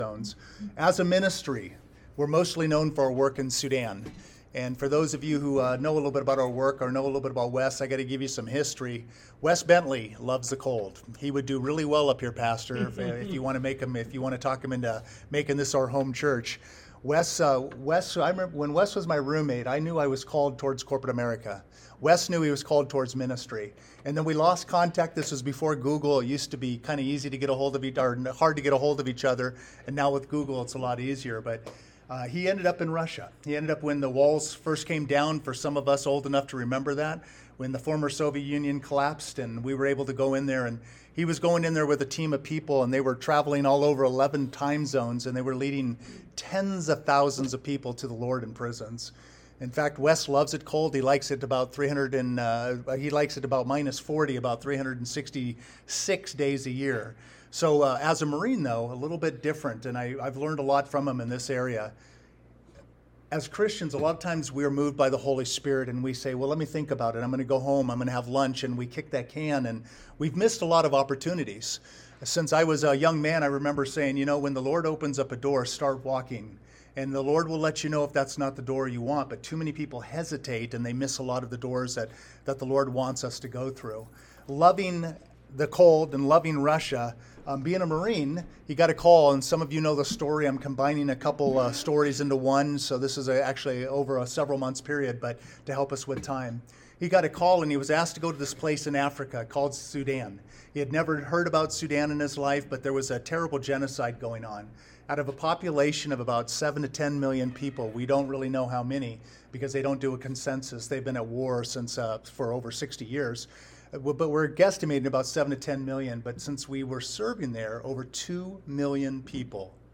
Series: Guest Speaker Service Type: Sunday Morning Topics: Missions